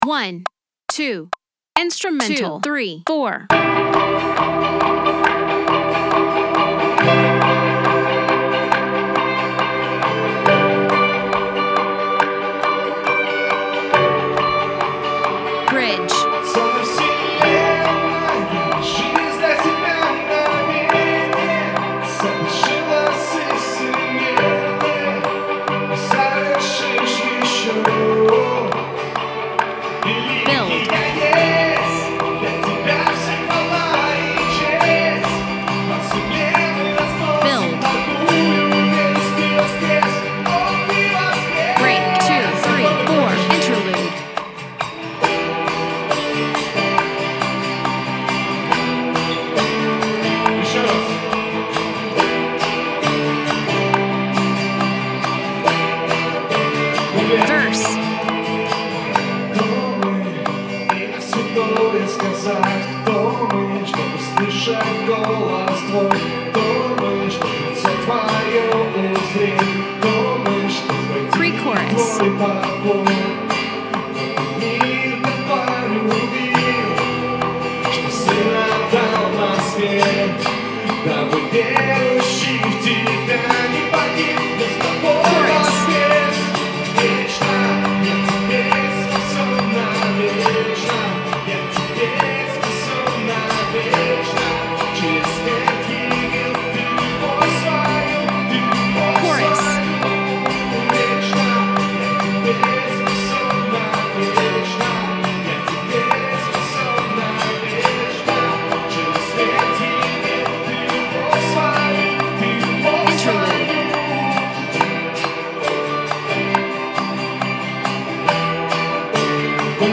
песня
357 просмотров 250 прослушиваний 2 скачивания BPM: 138